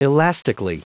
Prononciation du mot elastically en anglais (fichier audio)
Prononciation du mot : elastically